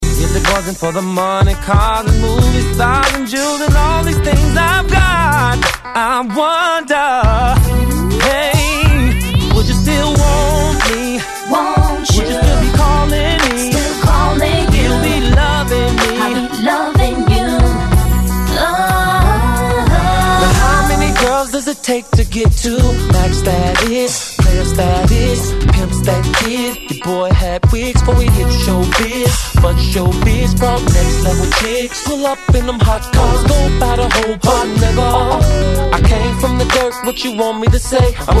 Назад в R'n'B